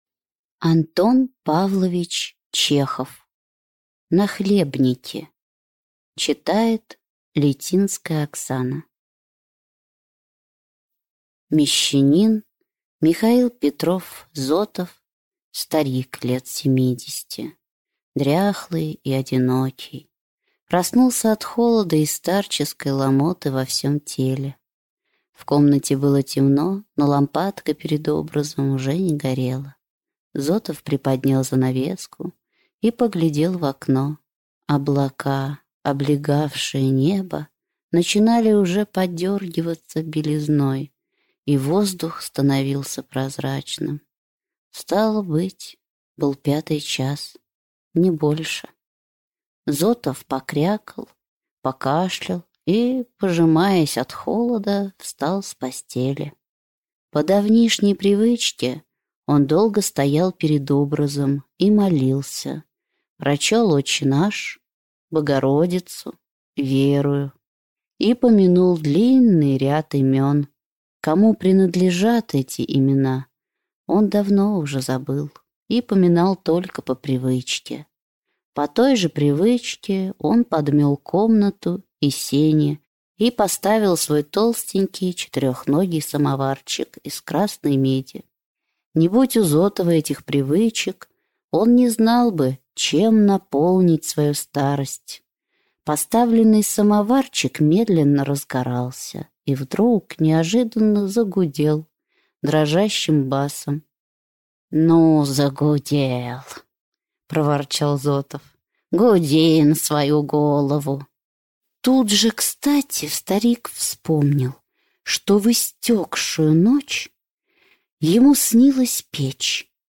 Aудиокнига Нахлебники